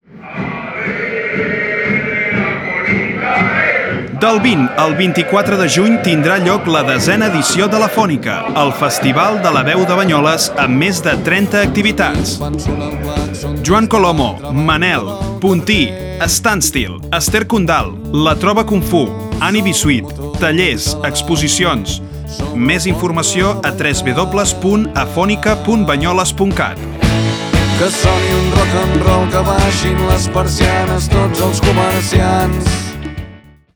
Falca de ràdio (a)phònica
falca_aphonica_2013_radio_banyoles.wav